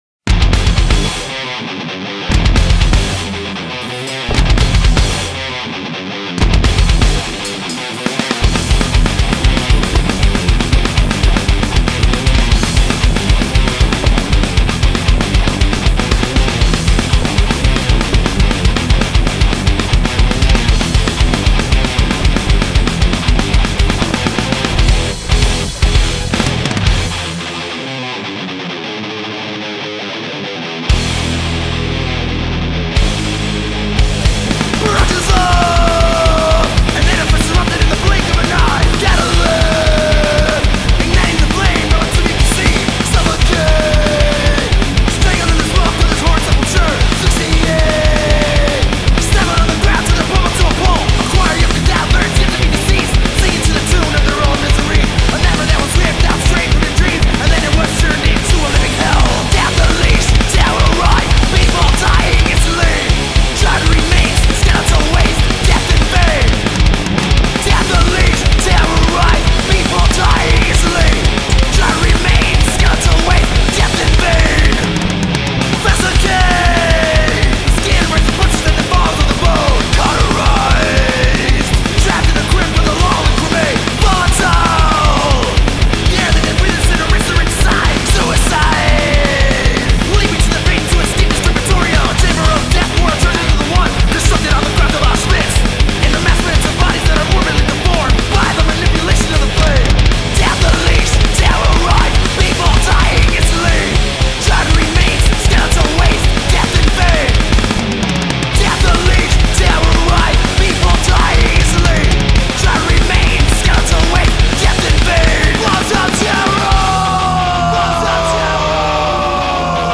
Speed Thrash